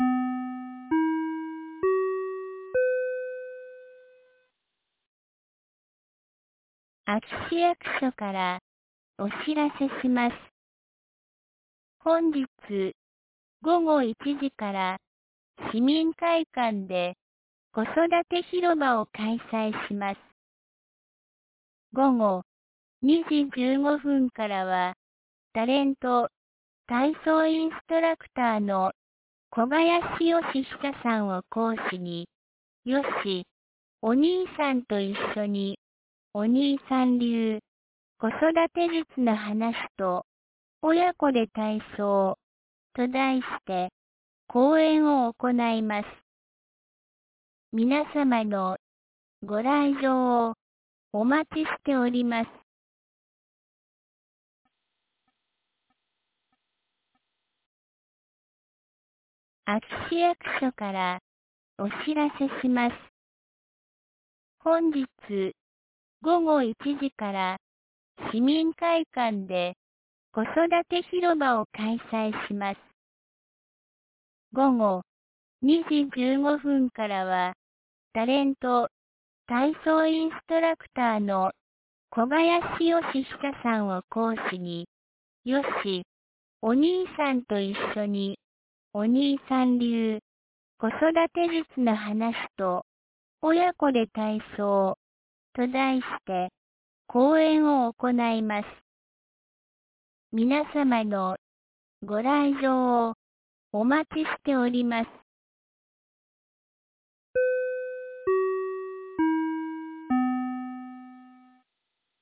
2024年09月14日 10時01分に、安芸市より全地区へ放送がありました。